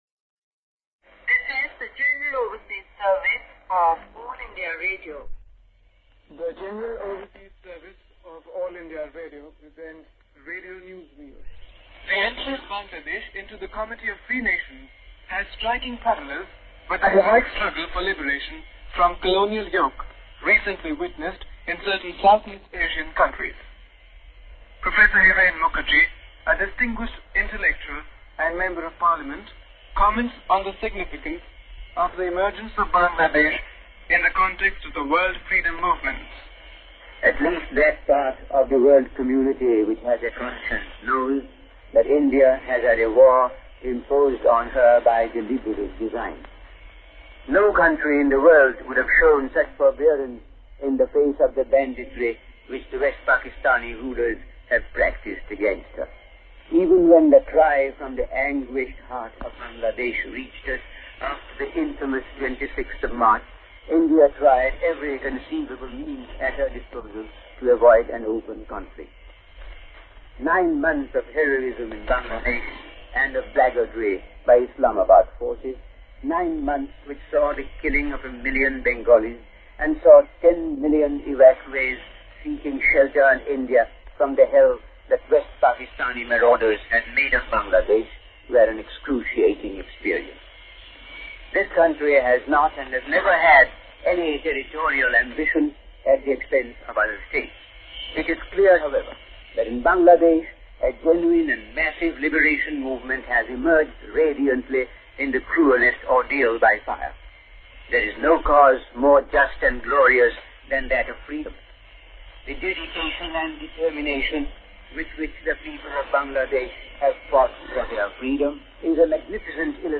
Shortwave transmissions from All India Radio during the Indo-Pakistan War (1971).